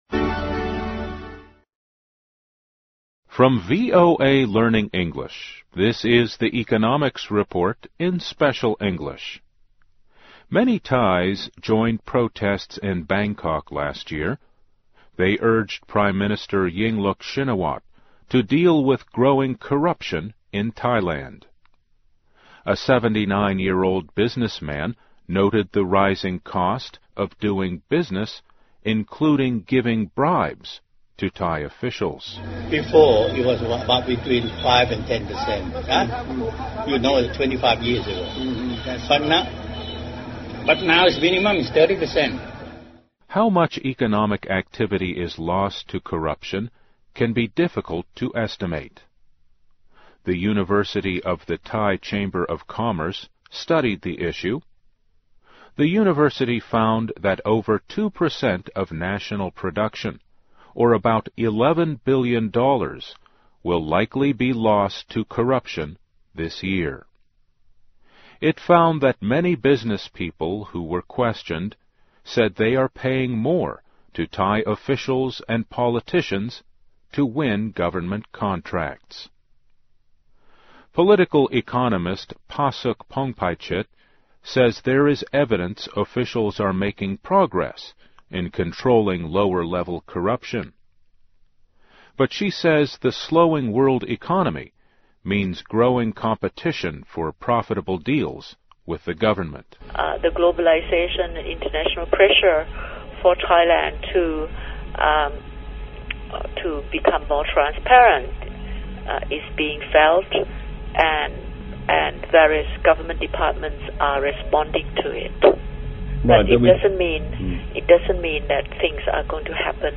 VOA慢速英语2013 经济报道 - 亚洲腐败问题拖累经济增长 听力文件下载—在线英语听力室